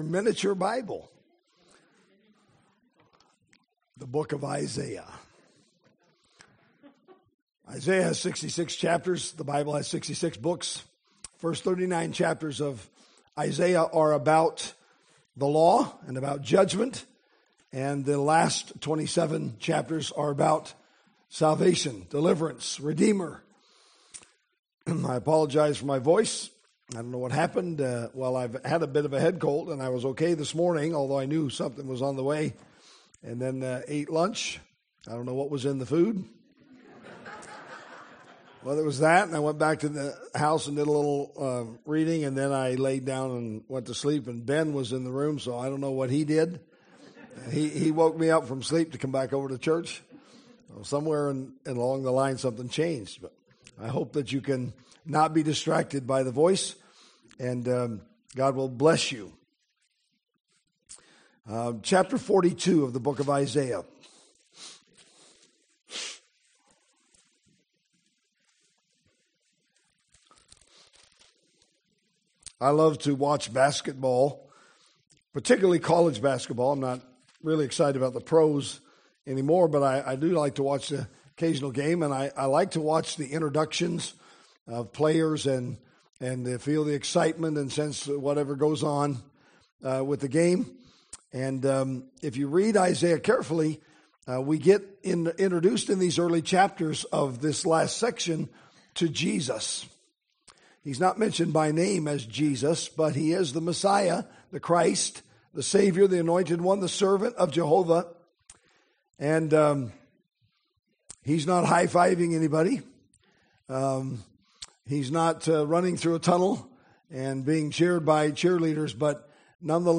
Sermons | New Testament Baptist Church